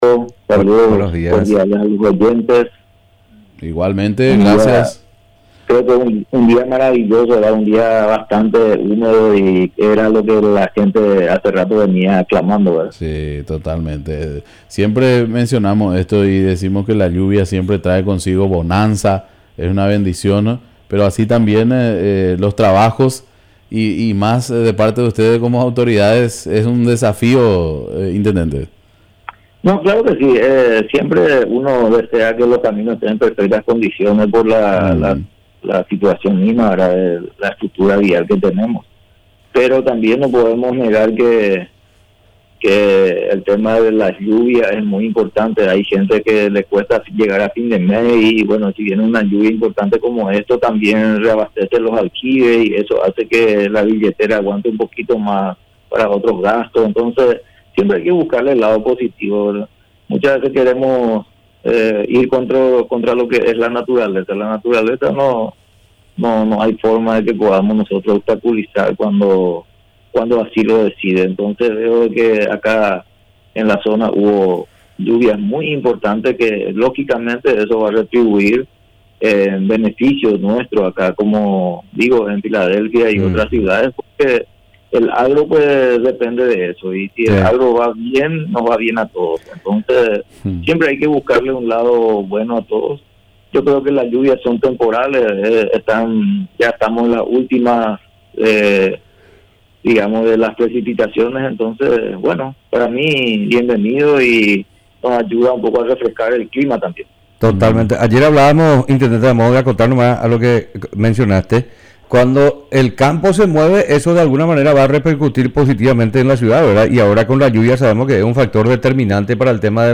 Entrevistas / Matinal 610 Informe, situación de las calles en la ciudad Mar 24 2025 | 00:28:35 1x 00:00 / 00:28:35 Subscribe Share RSS Feed Share Link Embed